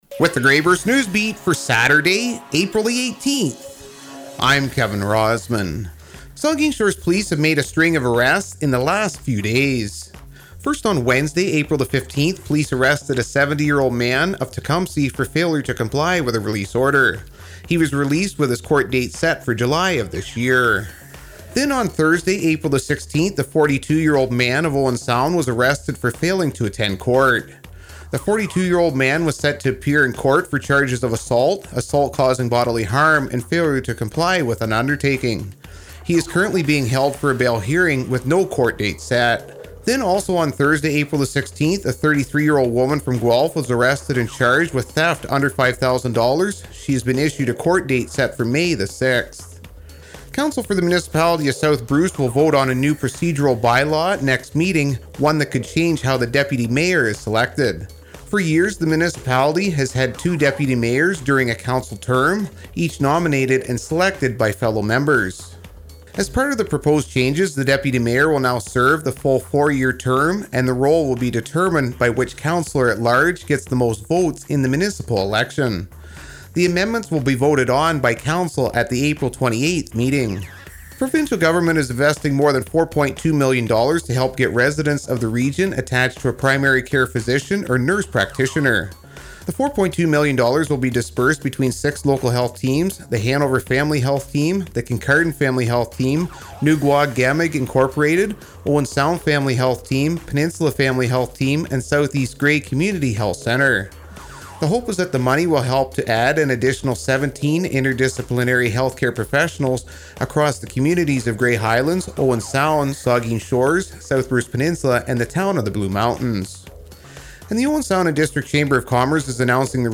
Today’s Local News from the Grey-Bruce Region, Produced Daily by Bluewater Radio